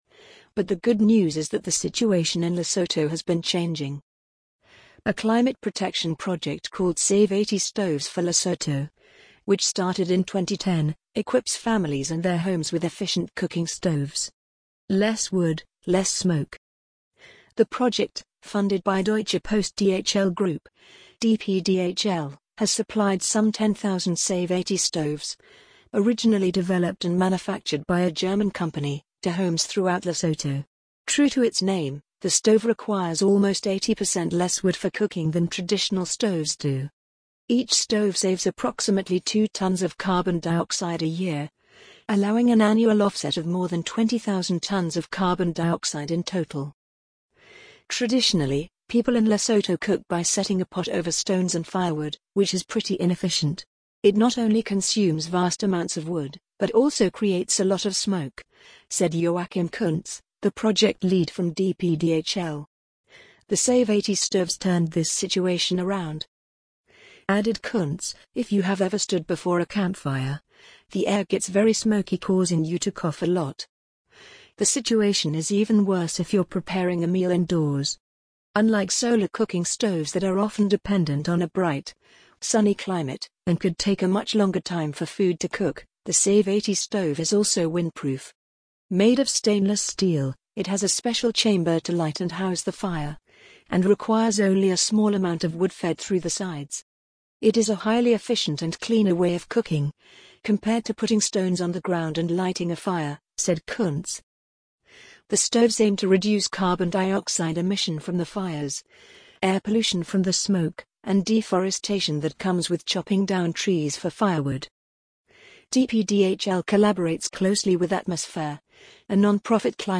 amazon_polly_2830.mp3